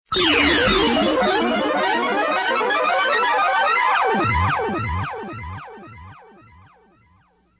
Section#1-Sweepers, sound effects
All tracks encoded in mp3 audio lo-fi quality.
fx computer